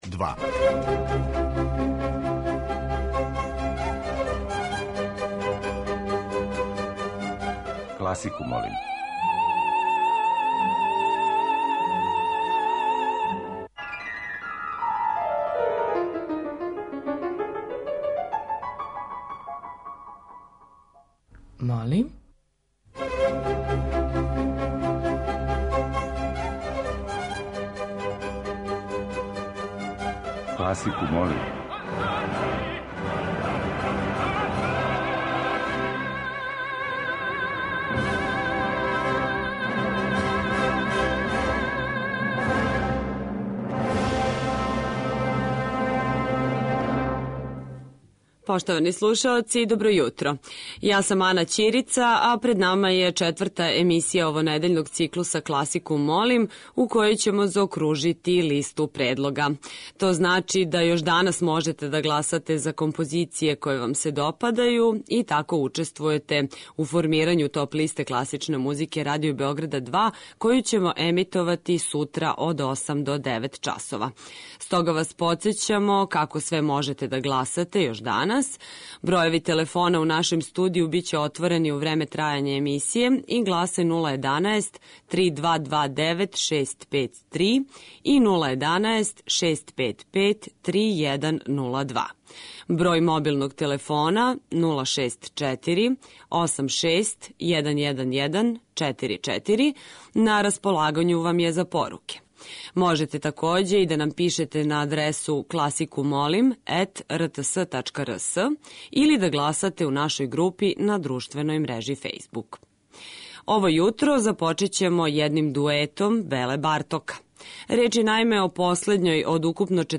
Листа предлога за недељни хит класичне музике Радио Београда 2 обједињена је музиком за удараљке.
Уживо вођена емисија, окренута широком кругу љубитеља музике, разноврсног је садржаја, који се огледа у подједнакој заступљености свих музичких стилова, епоха и жанрова.